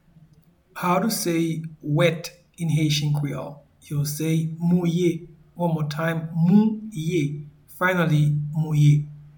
Pronunciation and Transcript:
Wet-in-Haitian-Creole-Mouye.mp3